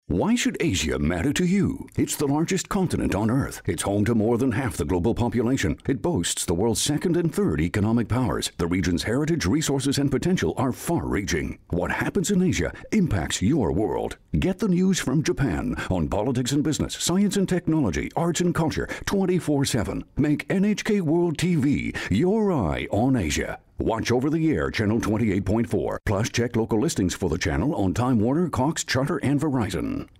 English inflection: Neutral North American
Tone: Baritone
NHKWTV-LA-Radio-Spot-2_COMP.mp3